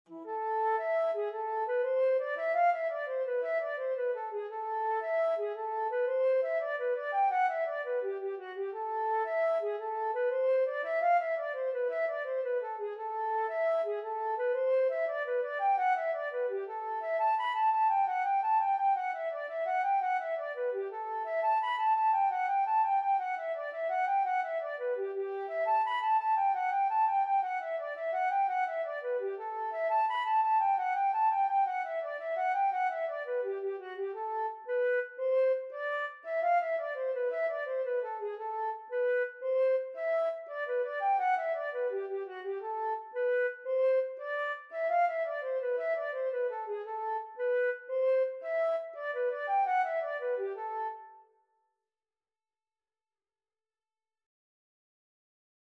Traditional Trad. Crabs in the Skillet (Irish Folk Song) Flute version
A minor (Sounding Pitch) (View more A minor Music for Flute )
6/8 (View more 6/8 Music)
Flute  (View more Intermediate Flute Music)
Traditional (View more Traditional Flute Music)